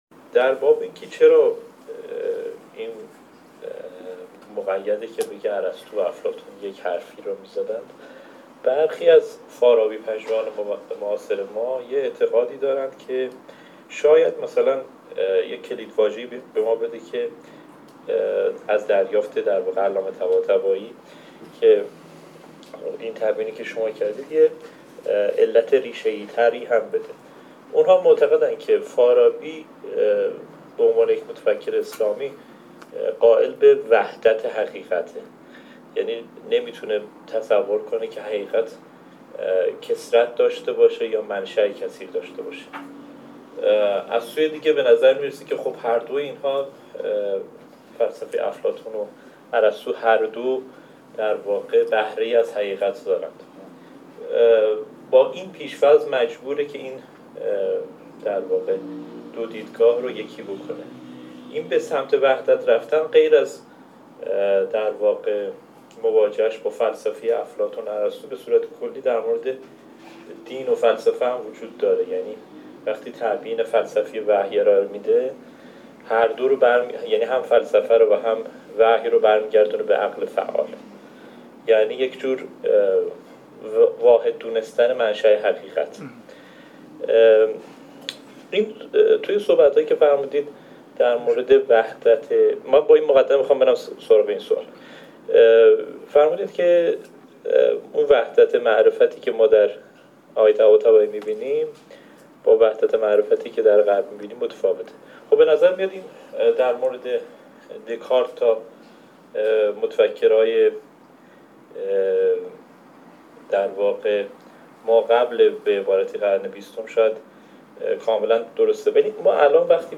پژوهشکده غرب شناسی و علم پژوهی برگزار می کند: